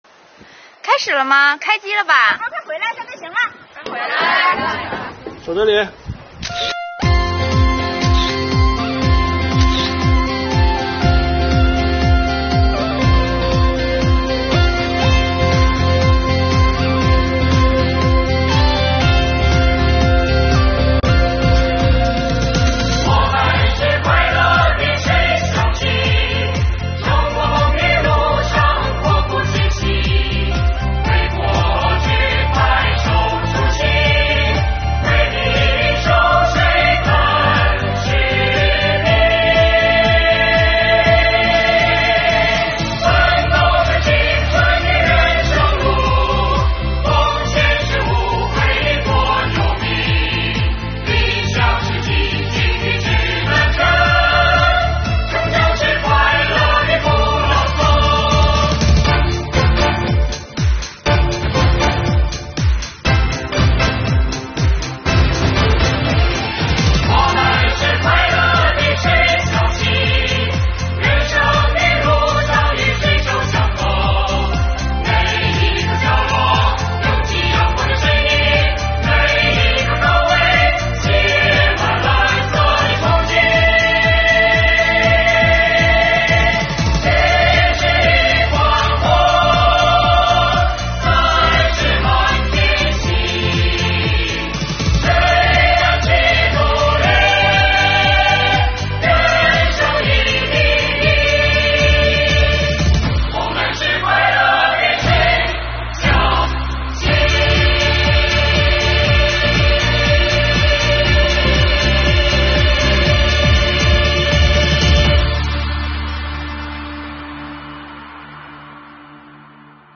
2022年新年伊始，我们用税务人录制的歌曲，为大家制作了一本音乐挂历，并将全年办税时间做了标注，伴着歌声开启新征程吧。
演唱：国家税务总局敦化市税务局干部